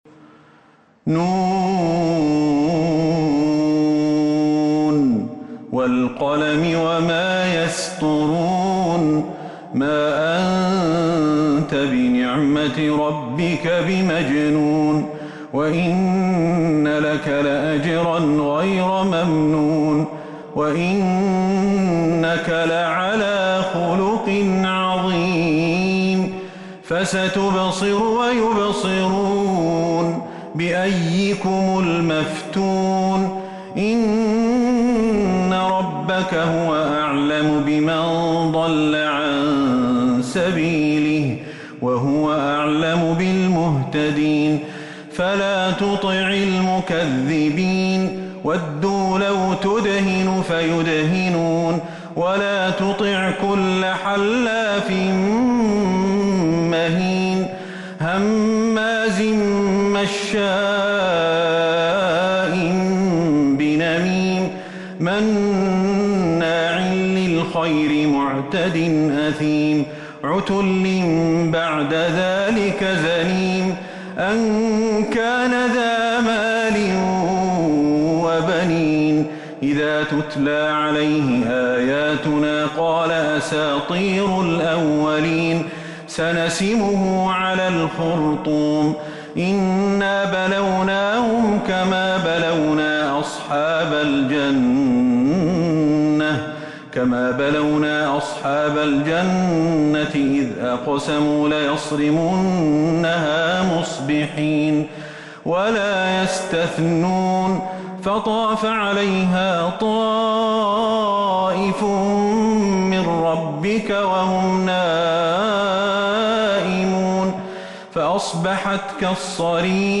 سورة القلم Surat Al-Qalam من تراويح المسجد النبوي 1442هـ > مصحف تراويح الحرم النبوي عام 1442هـ > المصحف - تلاوات الحرمين